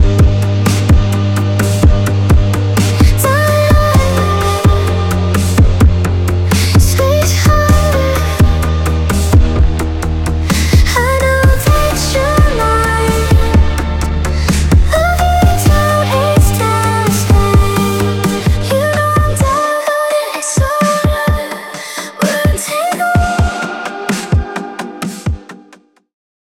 Generate up to 60 seconds of music with both accompaniment and vocals in a single pass, with vocals from lyrics and a reference track.
MiniMax-Electronic.wav